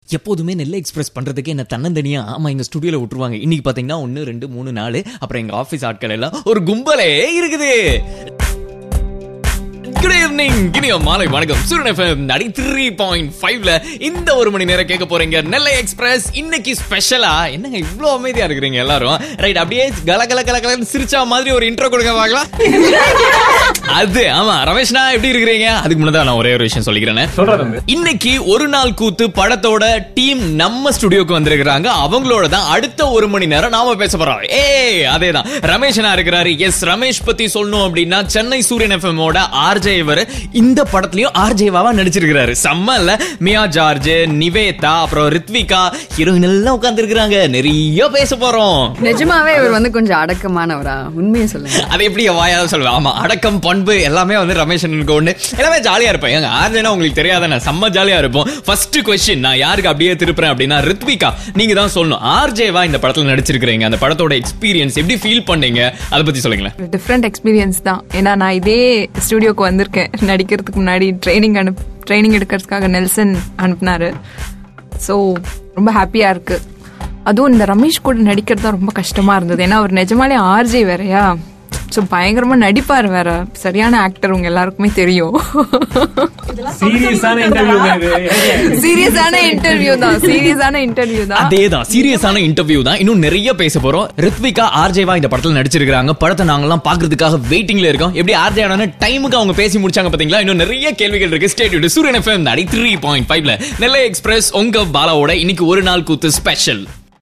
Interview oru nal koothu